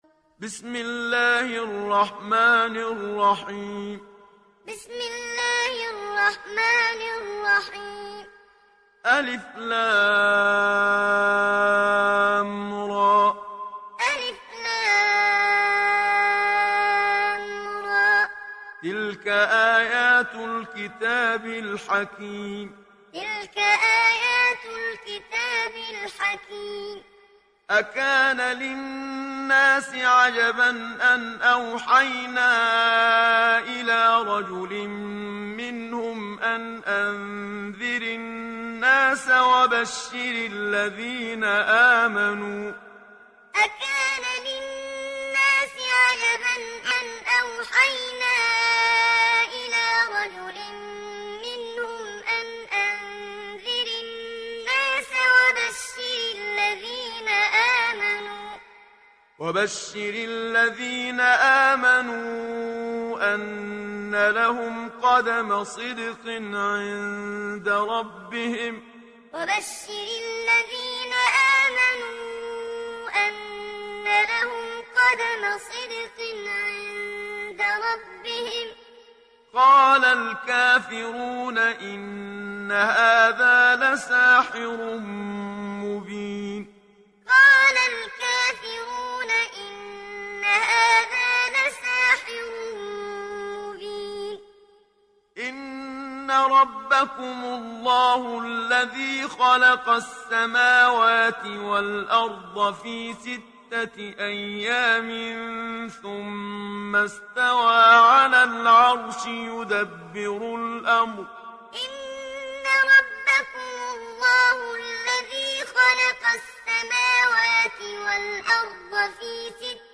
Sourate Yunus Télécharger mp3 Muhammad Siddiq Minshawi Muallim Riwayat Hafs an Assim, Téléchargez le Coran et écoutez les liens directs complets mp3